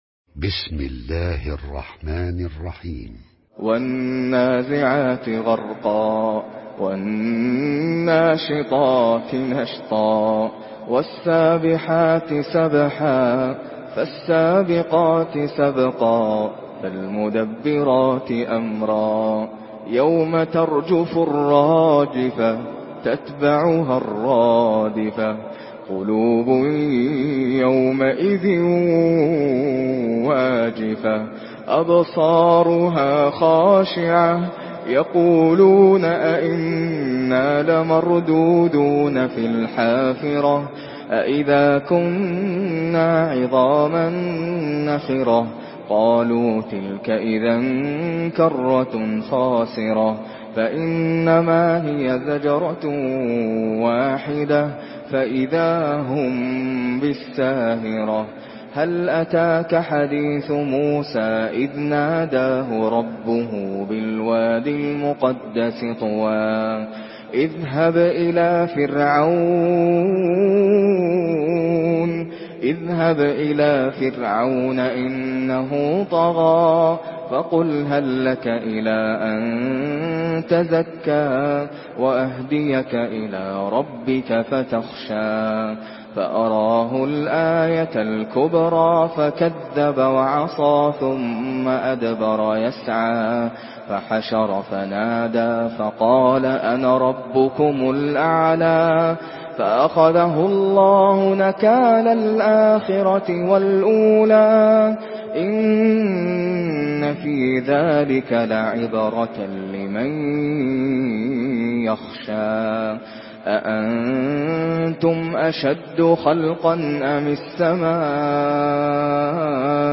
Surah النازعات MP3 by ناصر القطامي in حفص عن عاصم narration.